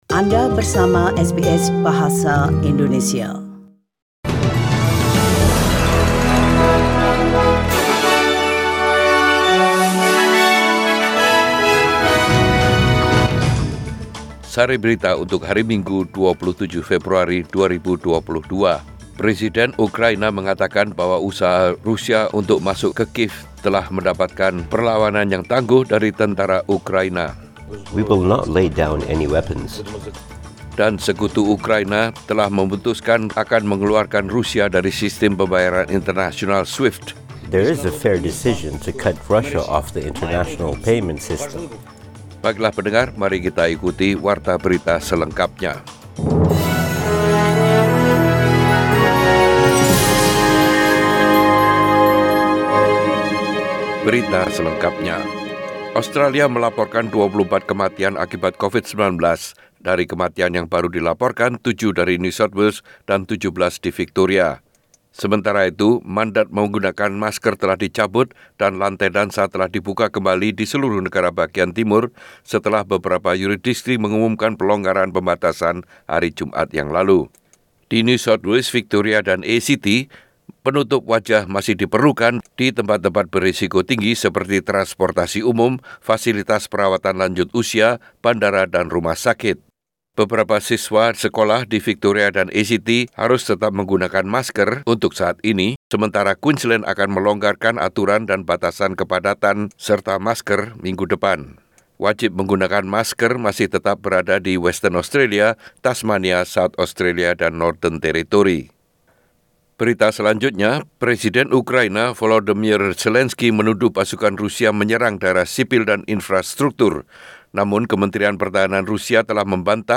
SBS Radio News in Bahasa Indonesia - 27 February 2022
Warta Berita Radio SBS Program Bahasa Indonesia.